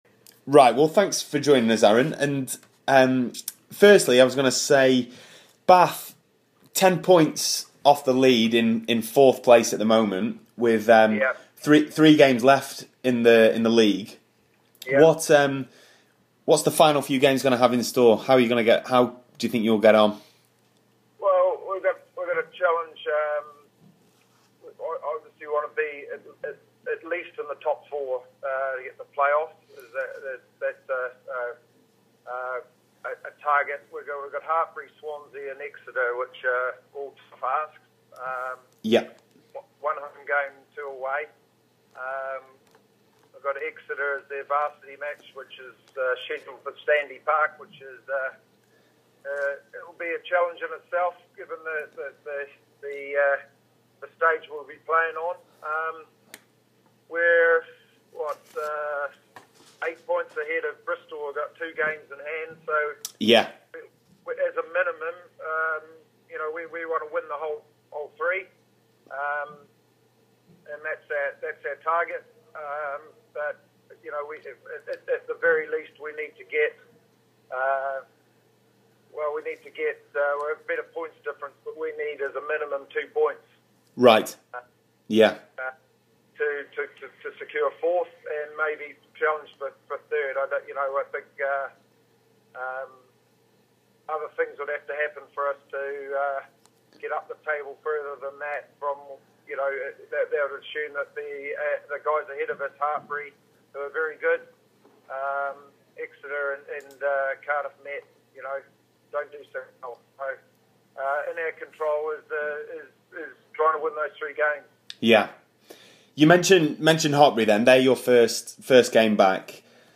Talking Rugby Union / Interview